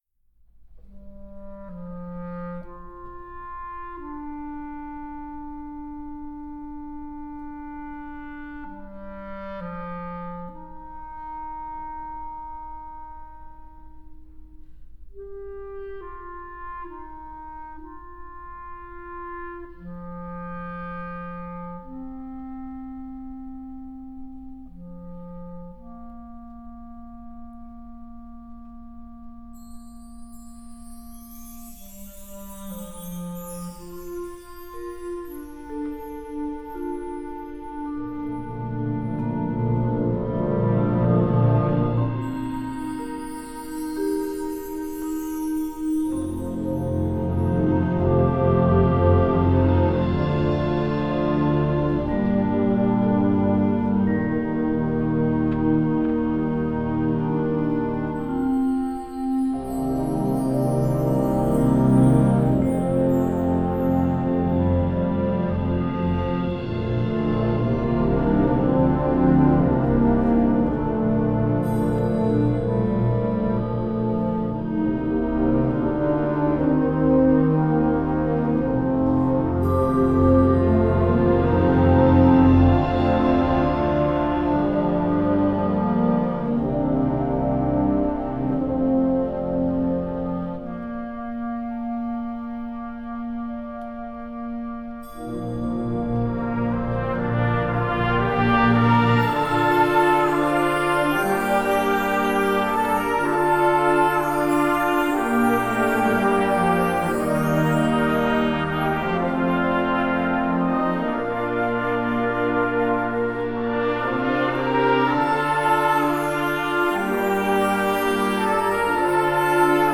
Instrumental Concert Band Concert/Contest
Simple, yet elegant and graceful,
With its poignant melody and beautiful chordal setting,
Concert Band